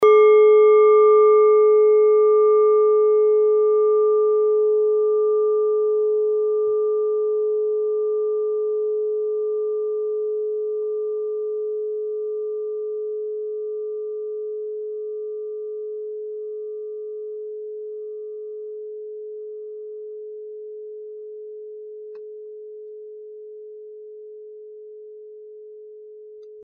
Klangschale Nepal Nr.24
Klangschale-Gewicht: 930g
Klangschale-Durchmesser: 14,7cm
(Ermittelt mit dem Filzklöppel)
ist der natürliche Kammerton bei 432Hz und dessen Ober- und Untertöne.
klangschale-nepal-24.mp3